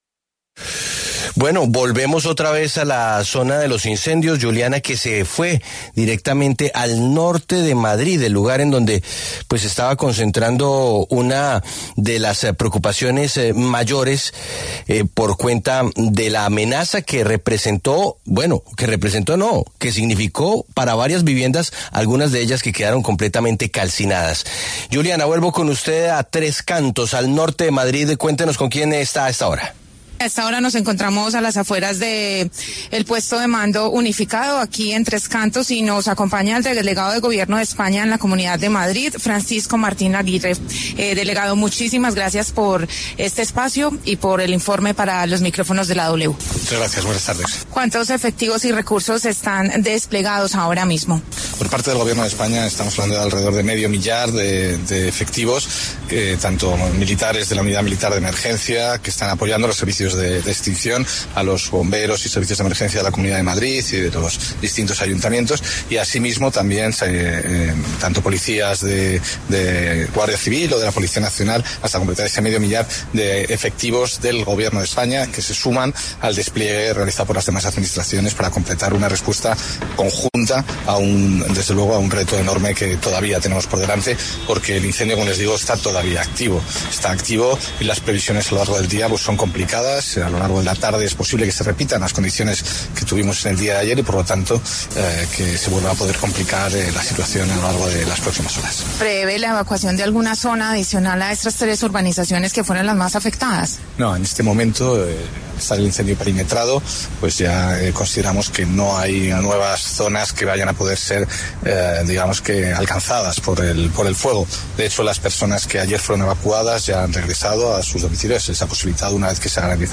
El delegado del Gobierno en Madrid, Francisco Martín, se refirió en La W al incendio originado en Tres Cantos, que se encuentra en fase de control y “estable”.